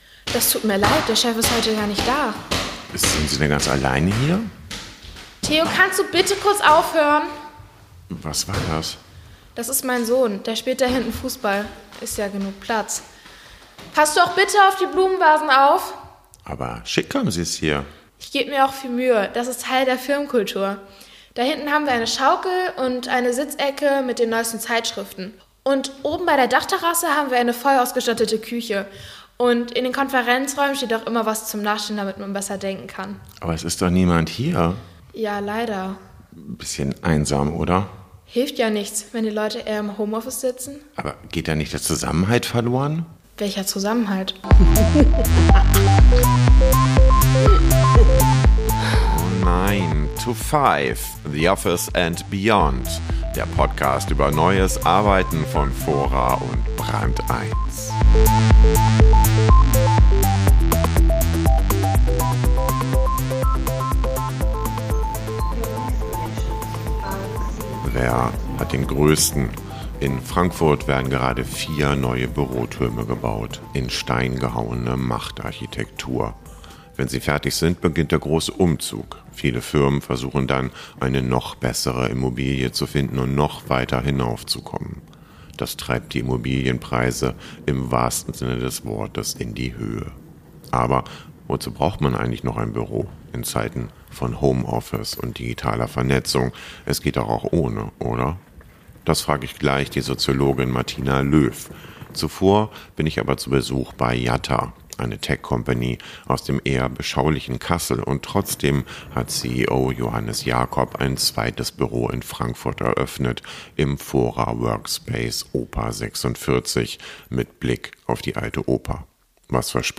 Aber sollte die Arbeitswelt von morgen nicht flexibel und ortsunabhängig sein? Und was hat das mit Spielplätzen und der Stadtplanung zu tun? Die Interviews wurden an der TU Berlin und im Fora Workspace Oper 46 in Frankfurt aufgenommen.